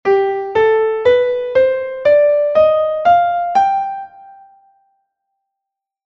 Escala Maior